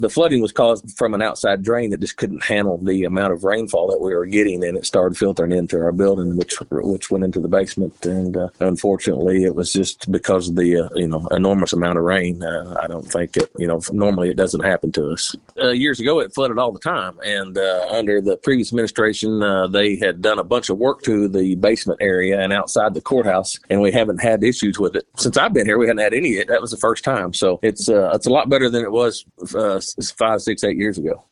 Judge Litty says this type of flooding is very rare for the Courthouse.